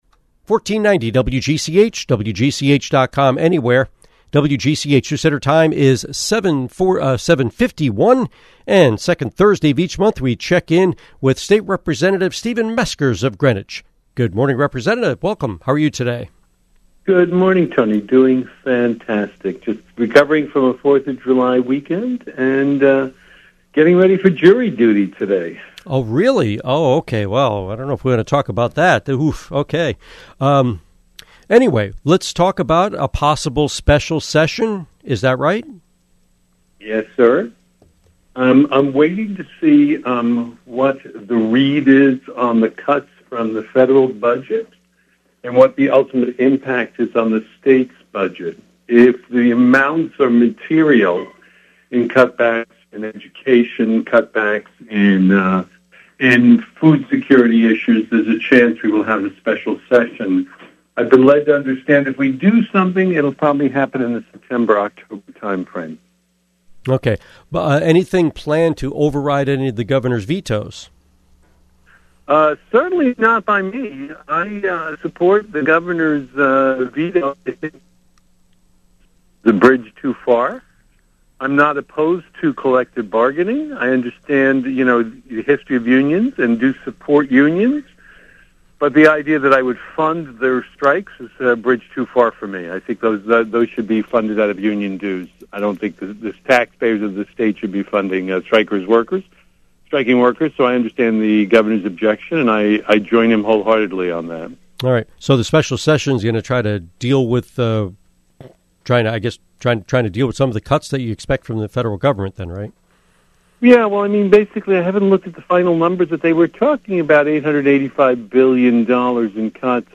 Interview with State Representative Stephen Meskers